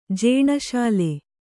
♪ jēṇa śale